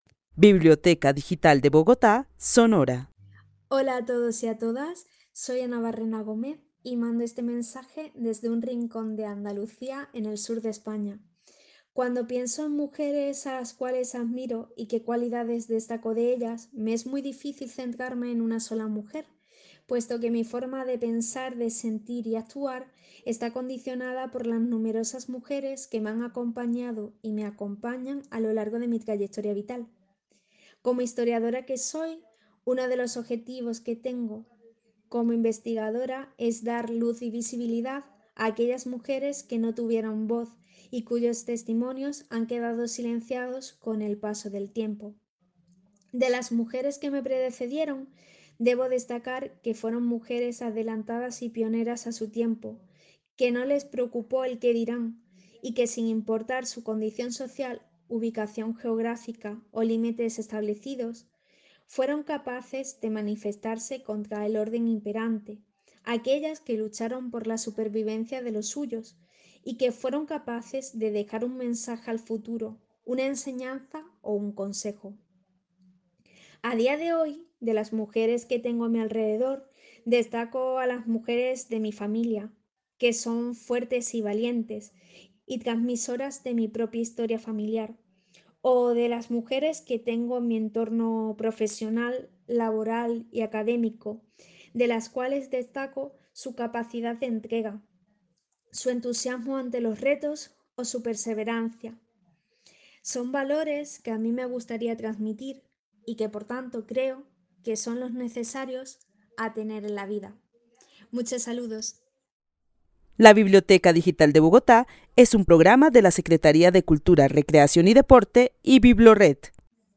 Narrativas sonoras de mujeres